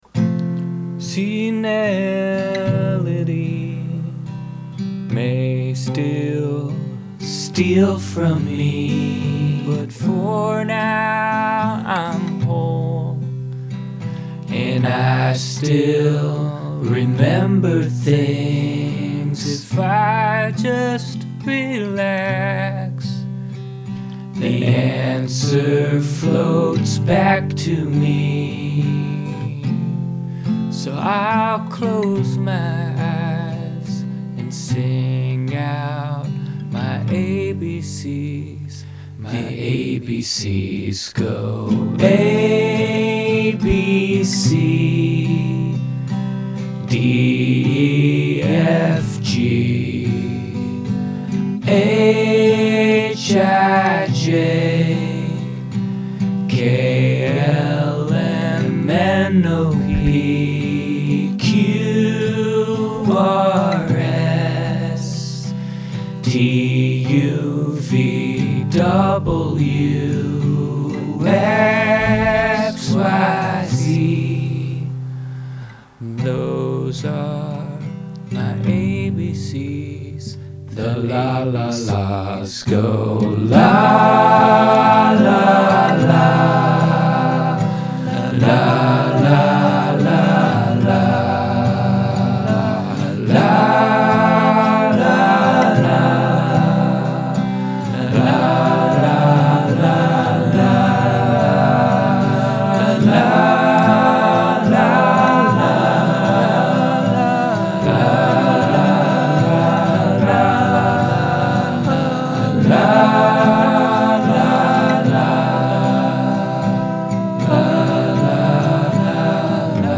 ||:F, C, G:||
verse, abc's, lalala's